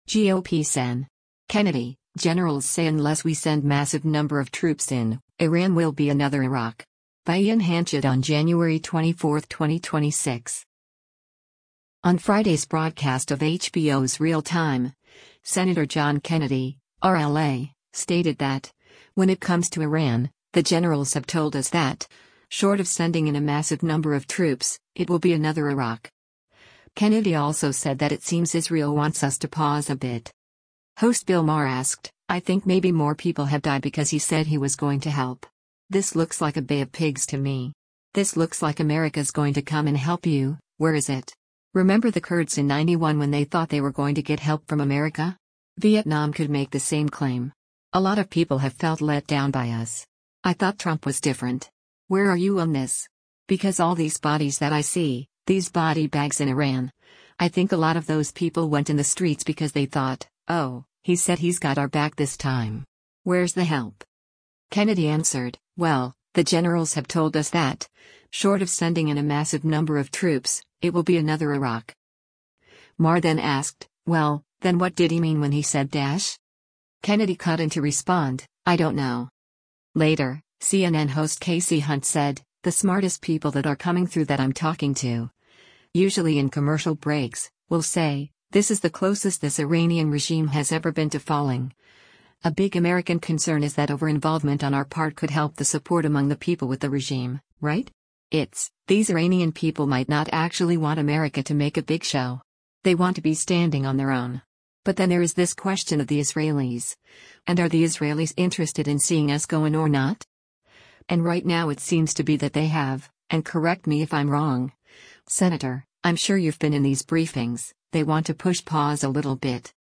On Friday’s broadcast of HBO’s “Real Time,” Sen. John Kennedy (R-LA) stated that, when it comes to Iran, “the generals have told us that, short of sending in a massive number of troops, it will be another Iraq.” Kennedy also said that it seems Israel wants us to pause a bit.